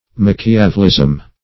Search Result for " machiavelism" : The Collaborative International Dictionary of English v.0.48: Machiavelism \Mach"i*a*vel*ism\, Machiavellianism \Mach`i*a*vel"lian*ism\, n. [Cf. F. machiav['e]lisme; It. machiavellismo.] The supposed principles of Machiavelli, or practice in conformity to them; political artifice, intended to favor arbitrary power.